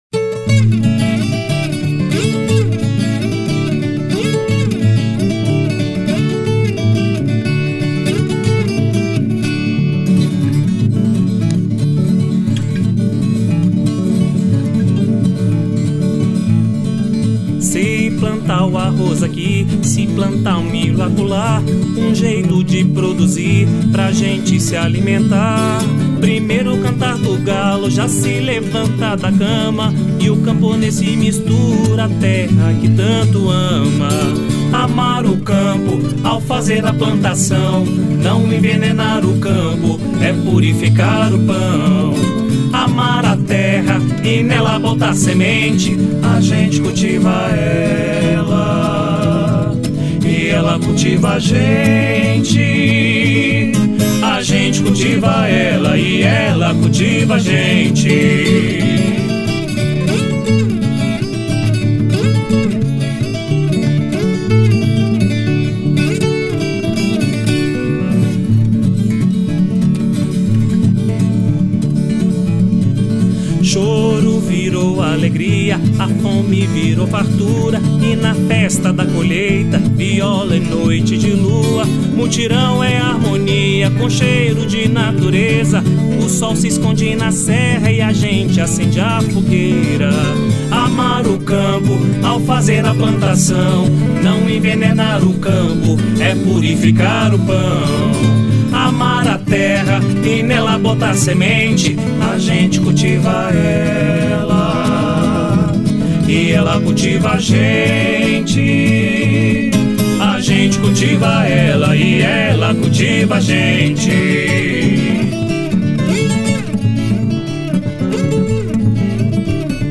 02:58:00   Ciranda